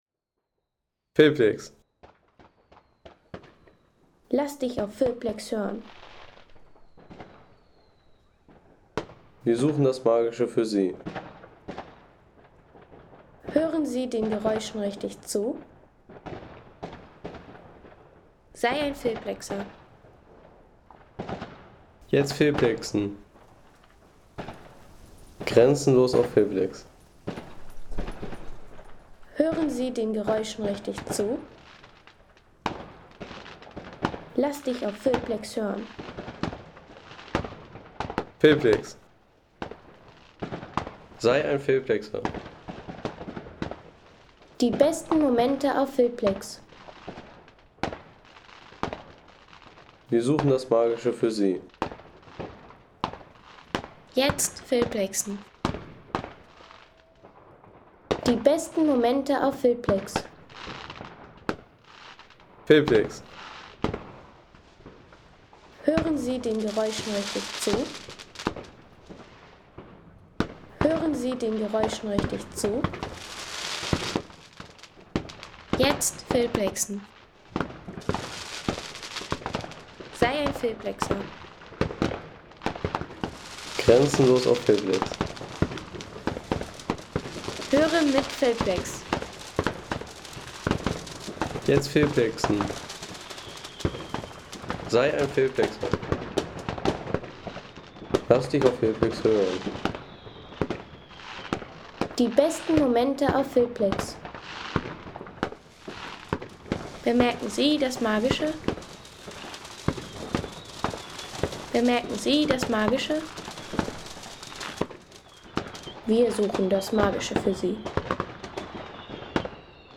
Bunte Silvesterstimmung
Feuerwerksspektakel in Flensburg - Silvesterstimmung in Gartenstadt.